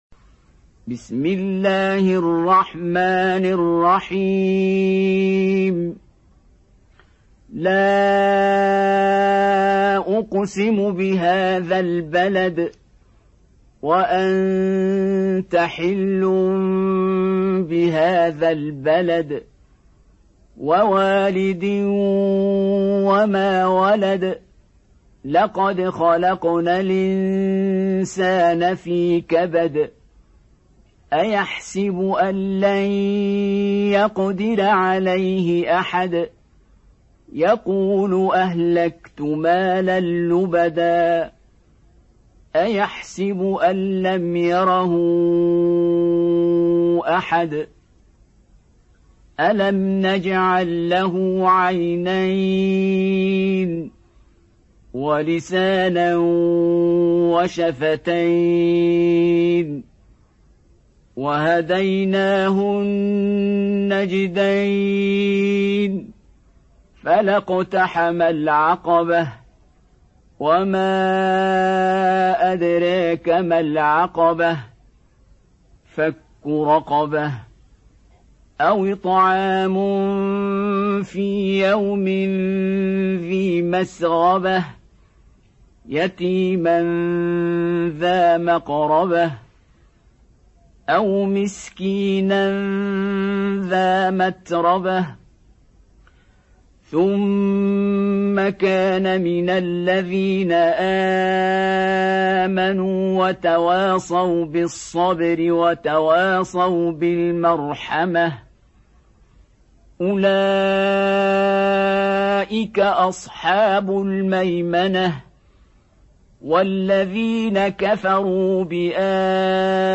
Surat Al Balad Download mp3 Abdul Basit Abd Alsamad Riwayat Warsh dari Nafi, Download Quran dan mendengarkan mp3 tautan langsung penuh